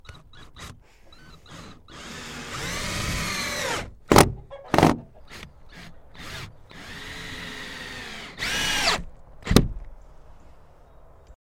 电动螺丝刀
描述：电动螺丝刀，精致又奇妙的声音。
Tag: 机械 金属 螺丝刀 工具 电动 起子